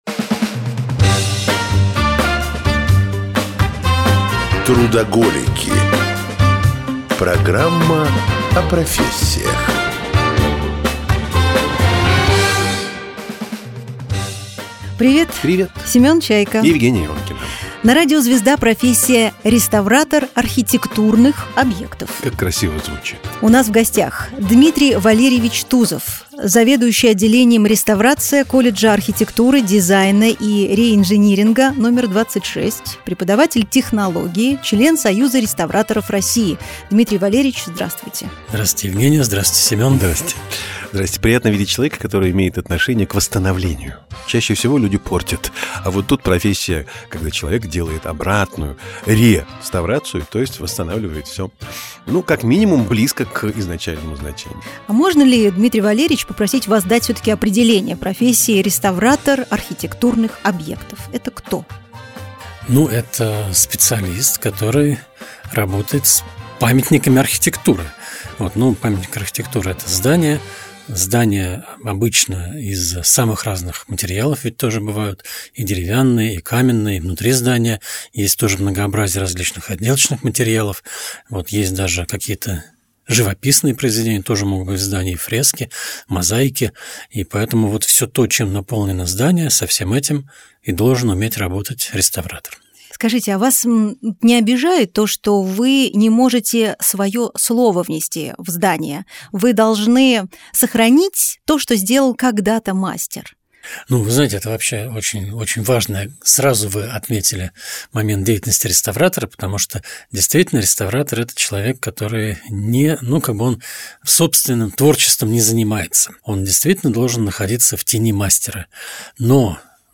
Сколько кают занимают россияне на МКС?В студии программы «Трудоголики» летчик-космонавт, Герой России, заместитель директора по науке Института медико-биологических проблем РАН Олег Котов.